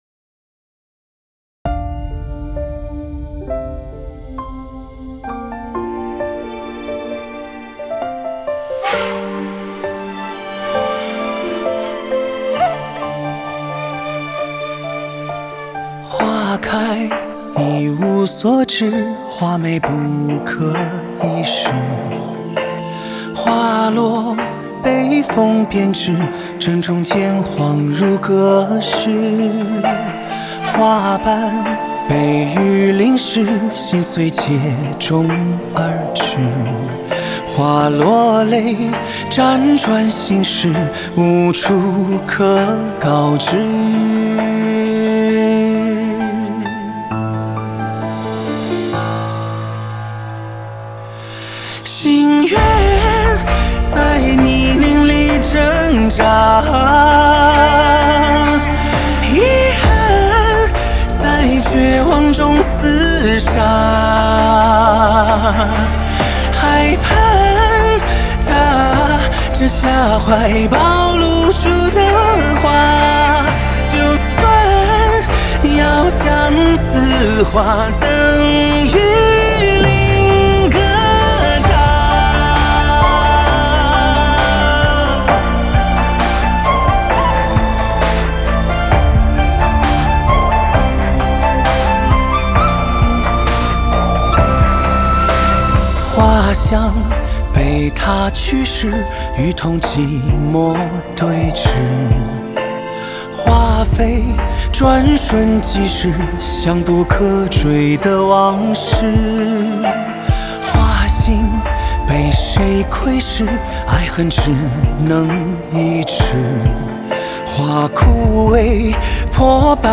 Ps：在线试听为压缩音质节选
影视剧片尾曲